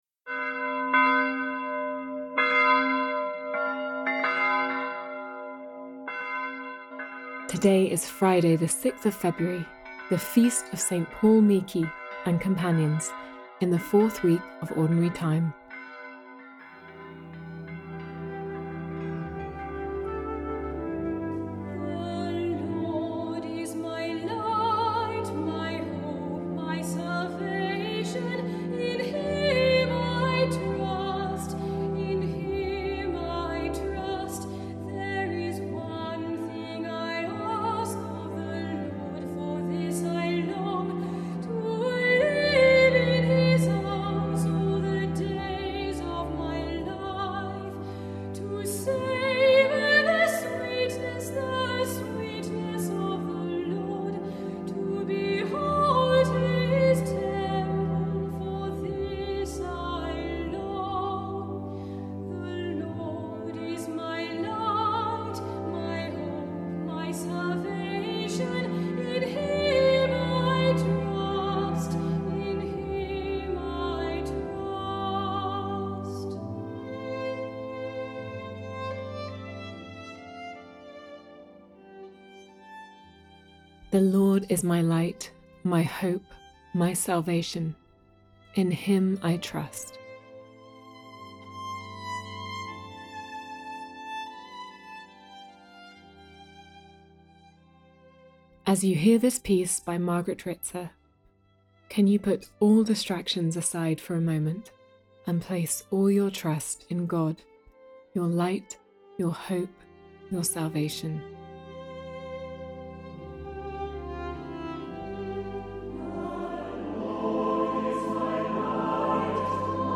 As you hear this piece by Margaret Rizza, can you put all distractions aside for a moment, and place all your trust in God, your light, your hope, your salvation? Today’s reading is from the Gospel of Mark.